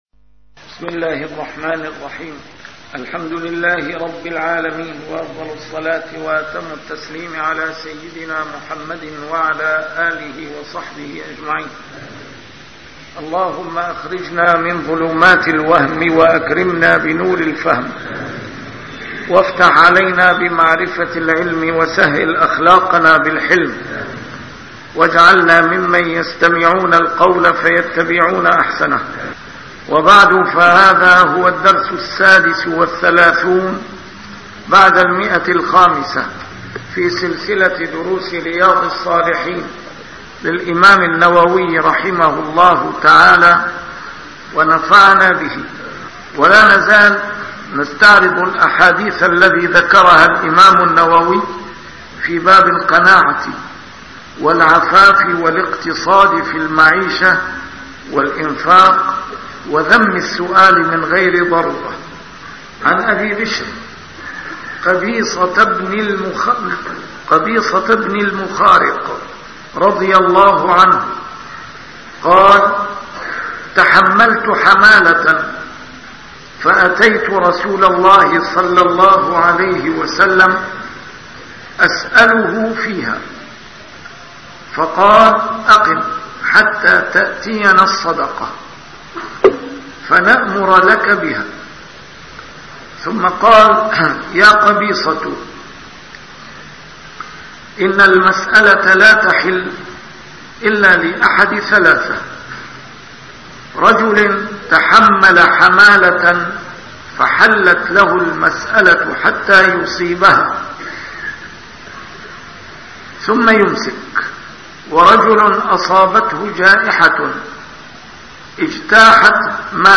A MARTYR SCHOLAR: IMAM MUHAMMAD SAEED RAMADAN AL-BOUTI - الدروس العلمية - شرح كتاب رياض الصالحين - 536- شرح رياض الصالحين: القناعة